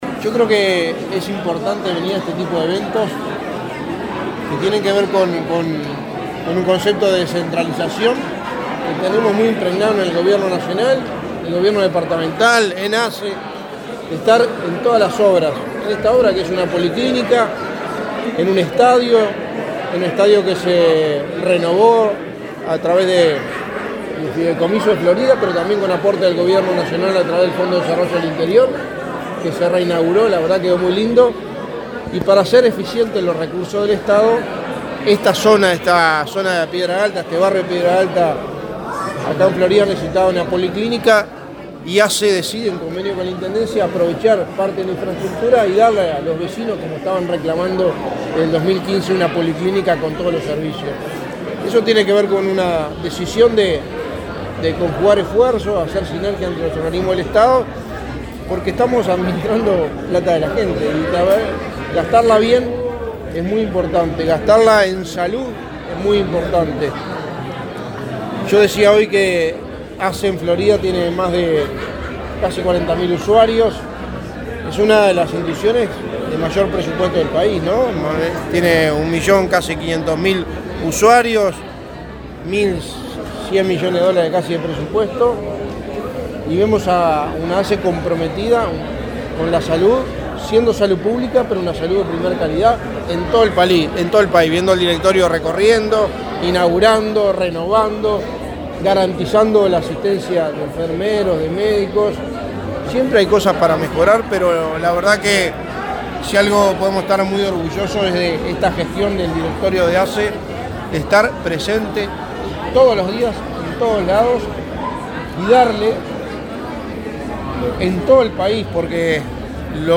Declaraciones a la prensa del secretario de Presidencia, Álvaro Delgado
Declaraciones a la prensa del secretario de Presidencia, Álvaro Delgado 18/06/2022 Compartir Facebook X Copiar enlace WhatsApp LinkedIn El presidente de la Administración de los Servicios de Salud del Estado (ASSE), Leonardo Cipriani, y el secretario de Presidencia de la República, Álvaro Delgado, participaron de la inauguración de la policlínica Campeones Olímpicos, en la ciudad de Florida. Luego, Delgado dialogó con la prensa.